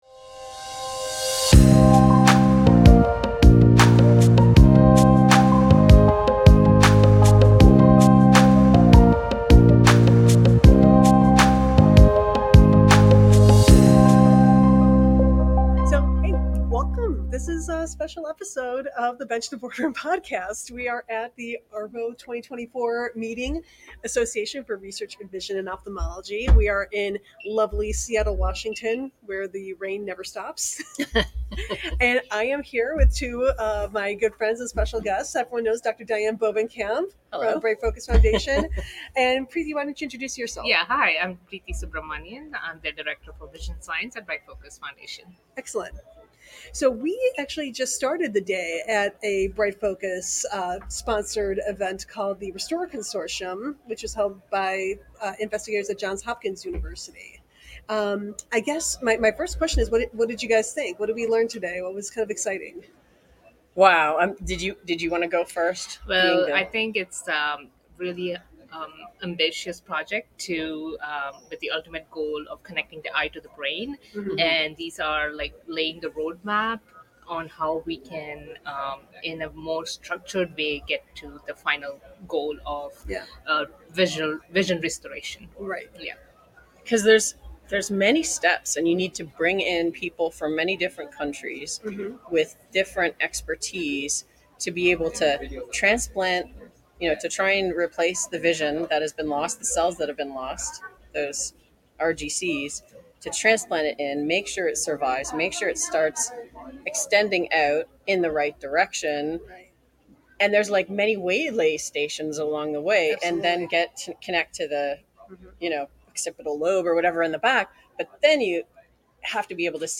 "Bench to Boardroom" is on the road! This week, we are in Seattle at the annual Association for Research in Vision and Ophthalmology (ARVO) meeting to see some amazing research, catch up with some old friends, and network and make some new ones!